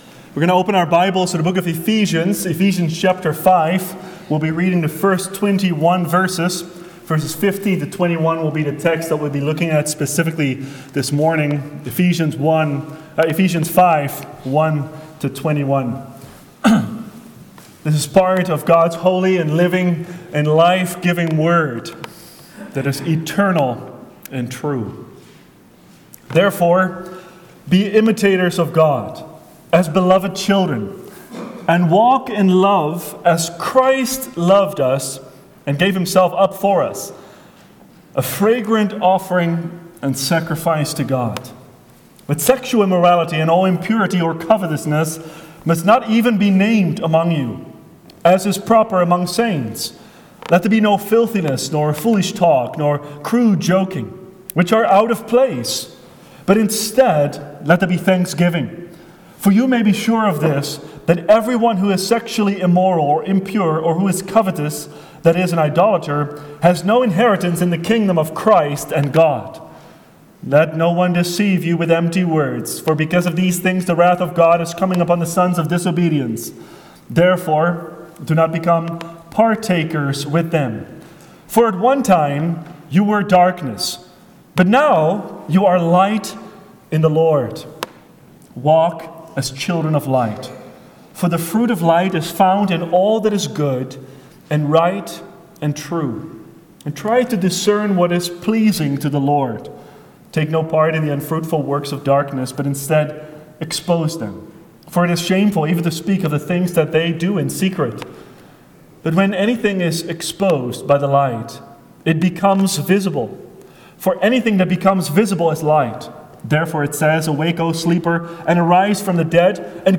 New Year's Day Sermons